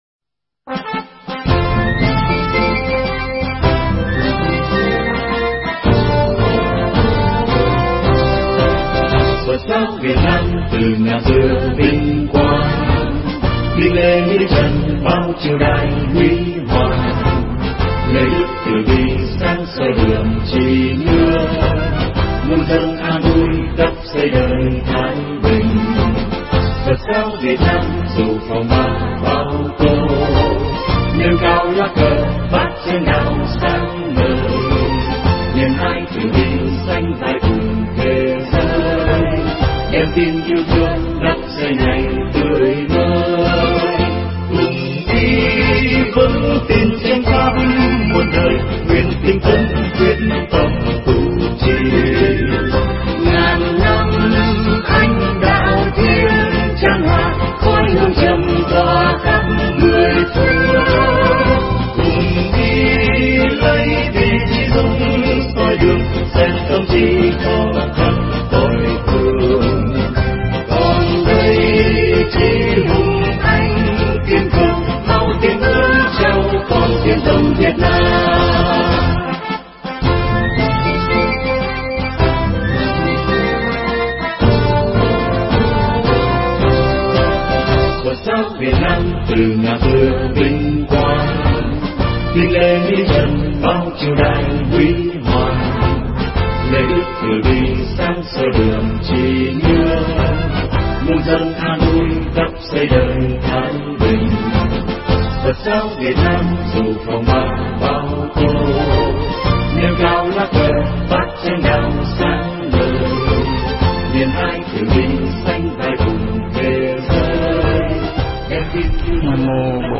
Mp3 Pháp Thoại Hóa giải nghiệp duyên 1
giảng tại chùa Thiền Tôn 2 Cát Lái, Quận 2, trong đạo tràng tu niệm Phật một ngày